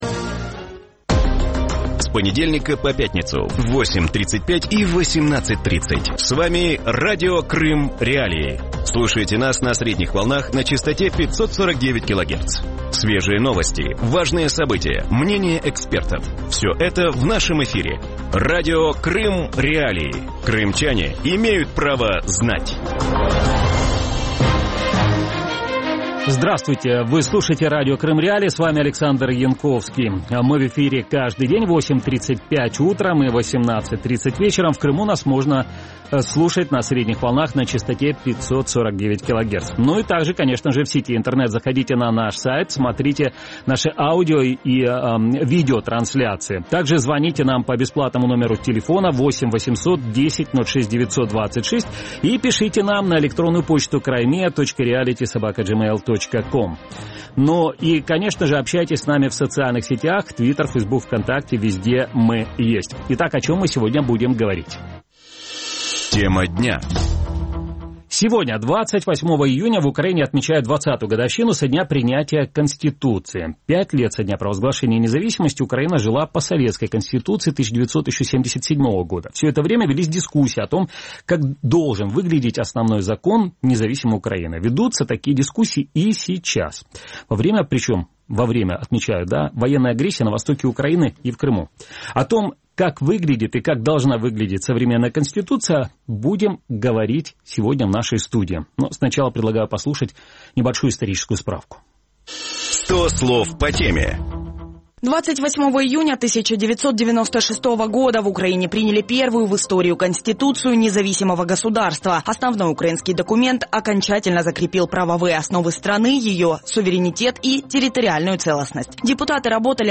В вечернем эфире Радио Крым.Реалии обсуждают украинскую Конституцию и возможные изменения в Основной закон. Как должна выглядеть конституция современного государства, нужно ли ее менять во время агрессии и под давлением других государств, а также какой вариант Конституции Украины был оптимальным – на эти вопросы ответит доктор юридических наук, бывший советник президента по конституционным вопросам Степан Гавриш.